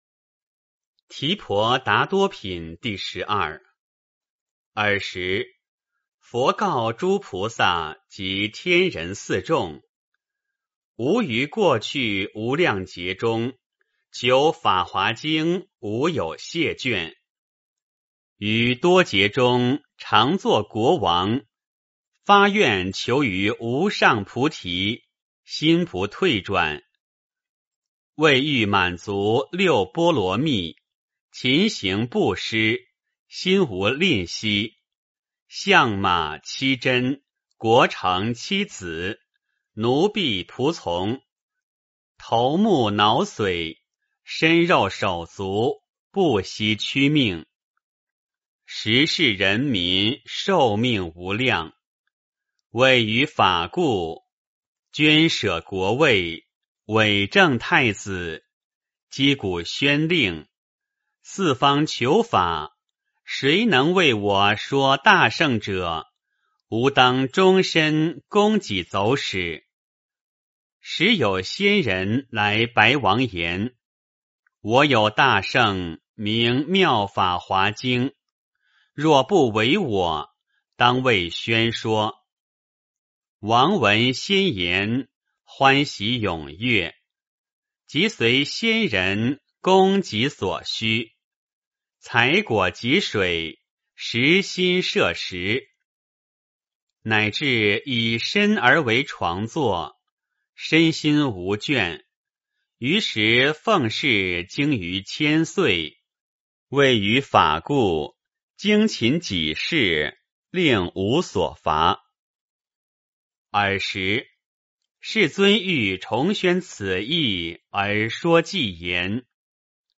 法华经-提婆达多品第十二 诵经 法华经-提婆达多品第十二--未知 点我： 标签: 佛音 诵经 佛教音乐 返回列表 上一篇： 法华经-授记品 下一篇： 法华经-劝持品第十三 相关文章 The Immigrant--Joanie Madden The Immigrant--Joanie Madden...